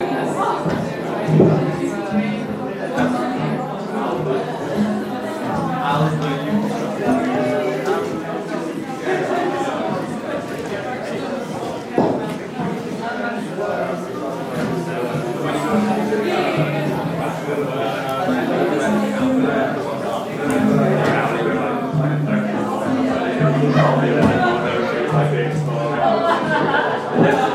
Room_noise2.mp3